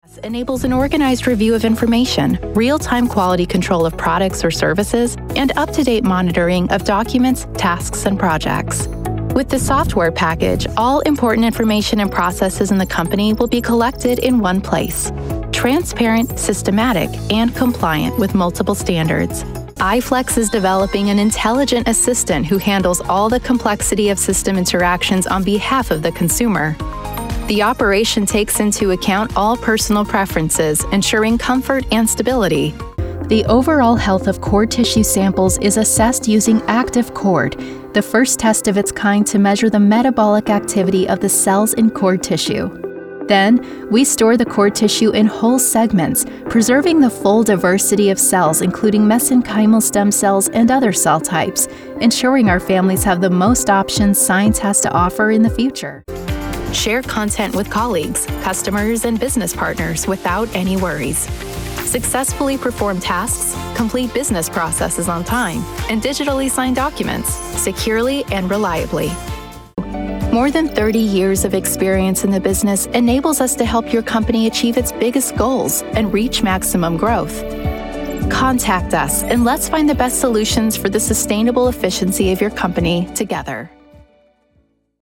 Voiceover Artist - Heartfelt Storytelling
Explainer Video VO Reel
Neutral English